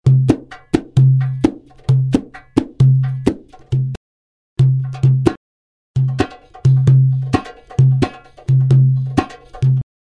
Royalty free music elements: Grooves
mf_SE-4687-oriental_percussion_5.mp3